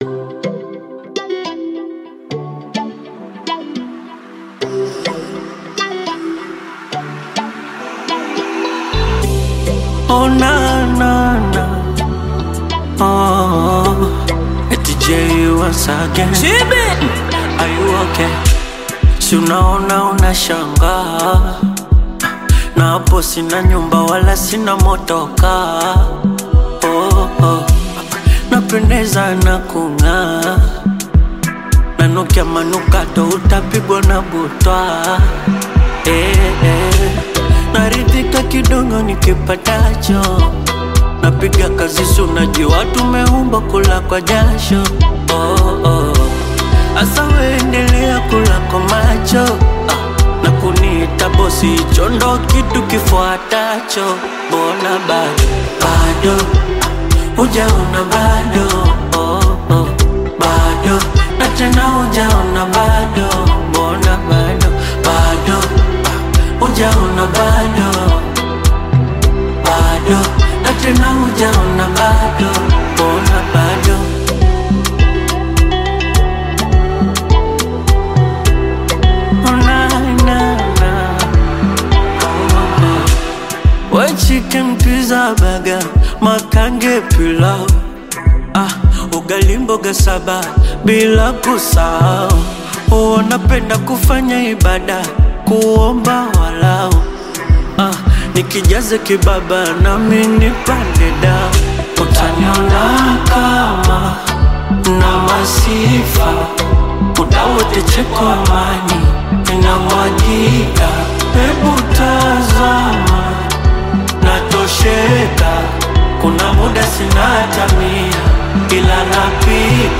smooth Bongo Flava single
Genre: Bongo Flava